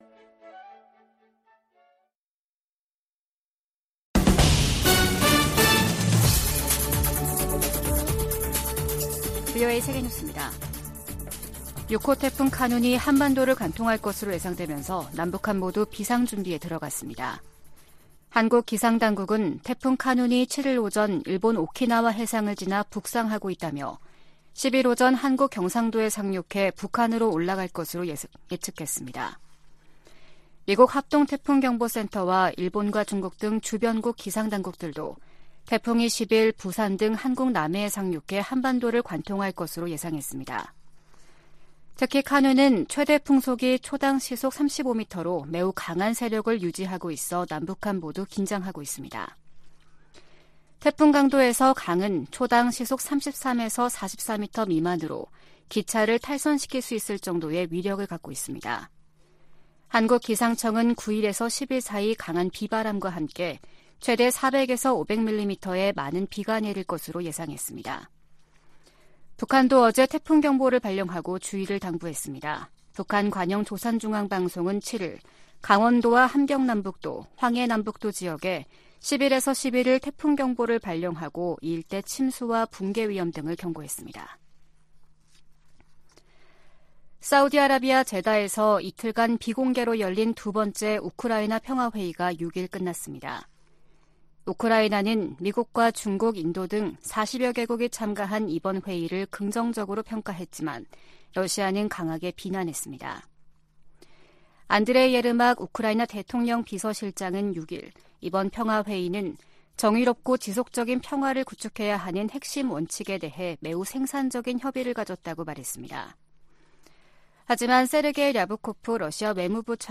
VOA 한국어 아침 뉴스 프로그램 '워싱턴 뉴스 광장' 2023년 8월 8일 방송입니다. 유엔 제재 대상 북한 유조선이 중국 해역에서 발견된 가운데 국무부는 모든 제재 위반을 심각하게 받아들인다고 밝혔습니다. 미국 민주당 상원의원들이 바이든 행정부에 서한을 보내 북한의 암호화폐 탈취에 대응하기 위한 계획을 공개할 것을 요구했습니다.